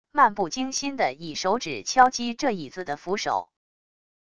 漫不经心的以手指敲击这椅子的扶手wav音频